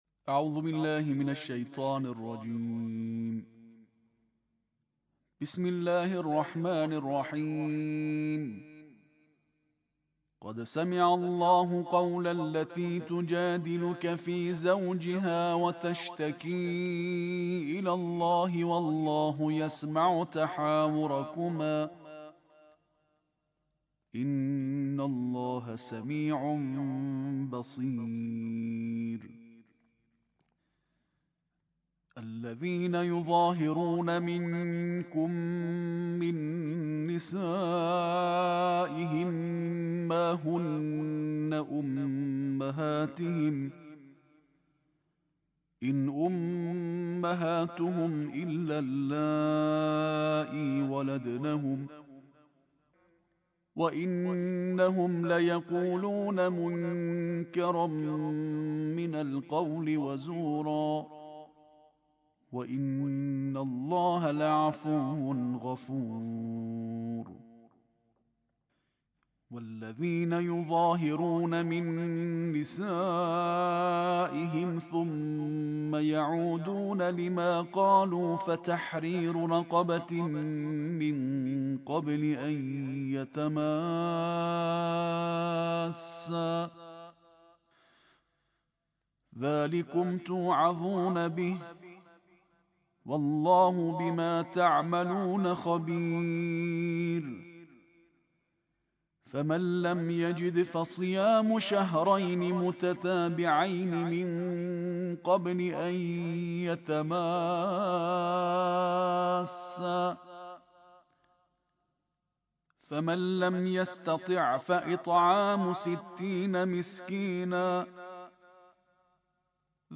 IQNA - Ramazan ayının 28. gününde İranlı seçkin kâri Kur’an-ı Kerim’in 28. cüzünü tertille okudu.